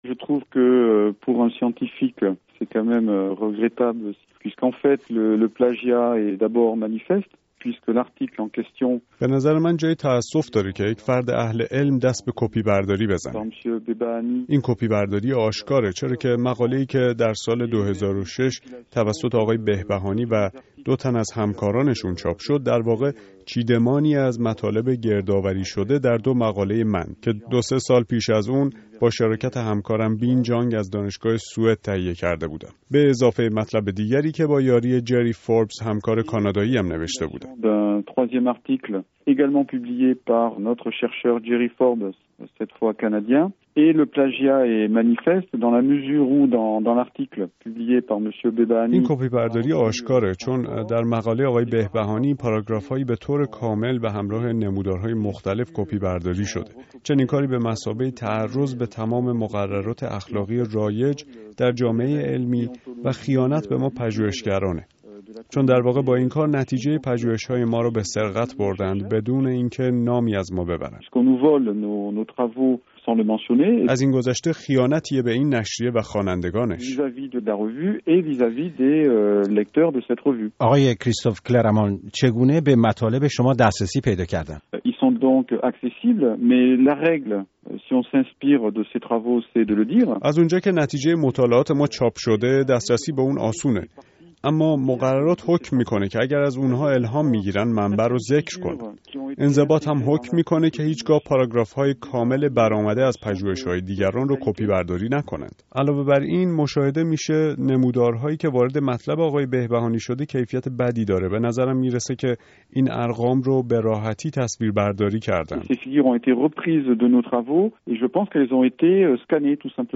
گفتکوی رادیو فردا